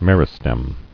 [mer·i·stem]